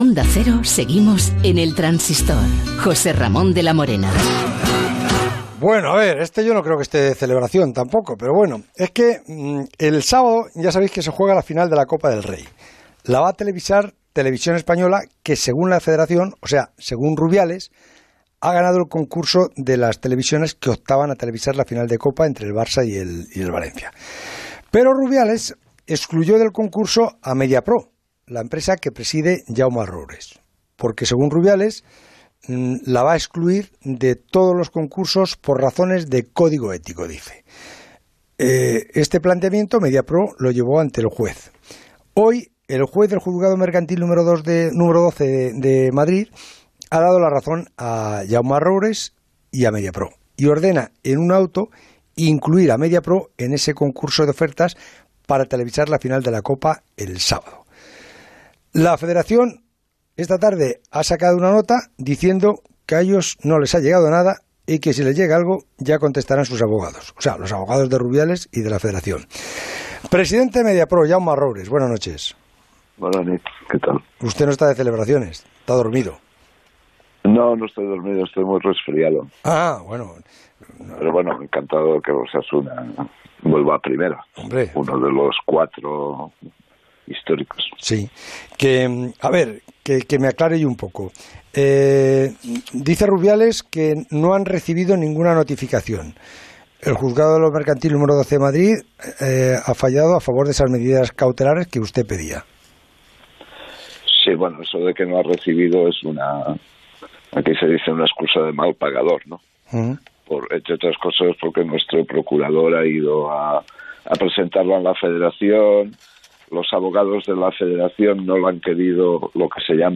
El Transistor entrevistó anoche a Jaume Roures, presidente de Mediapro, quien explicó los problemas que hay entre la Federación y su empresa por los derechos televisivos de la final de Copa, que se jugará el próximo sábado 25 entre Barcelona y Valencia.